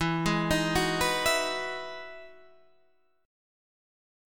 E Major 9th